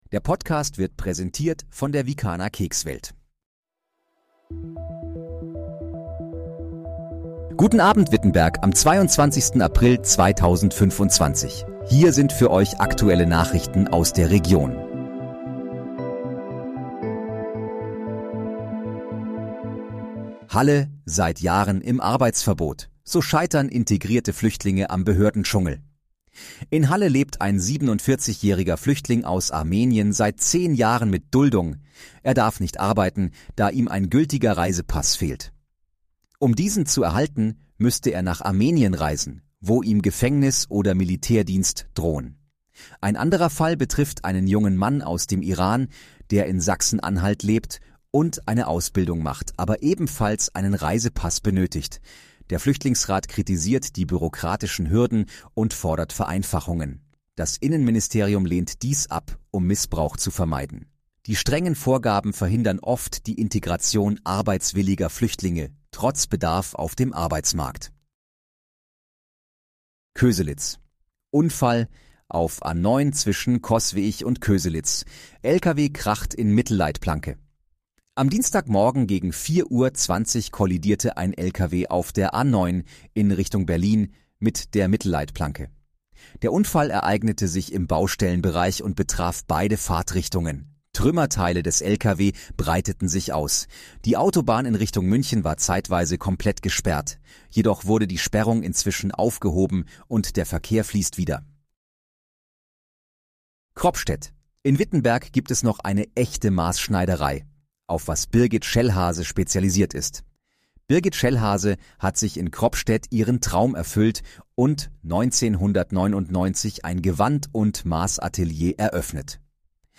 Guten Abend, Wittenberg: Aktuelle Nachrichten vom 22.04.2025, erstellt mit KI-Unterstützung
Nachrichten